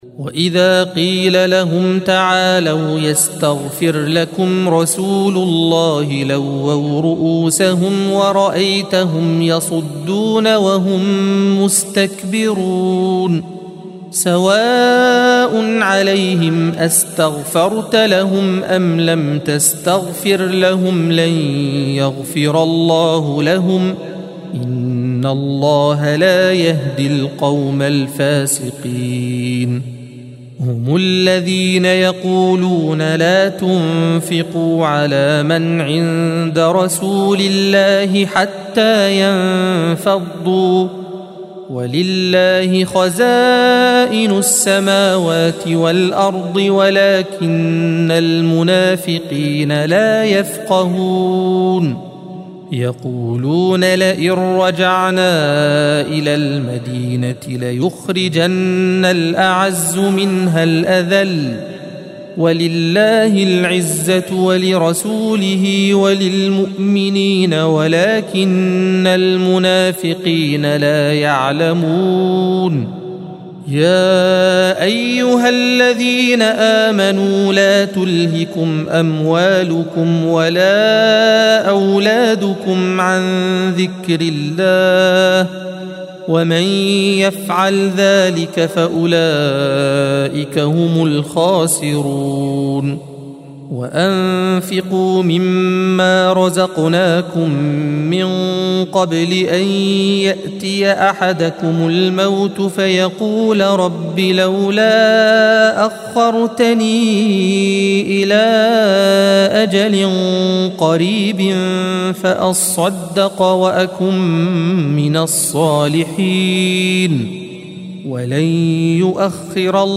الصفحة 555 - القارئ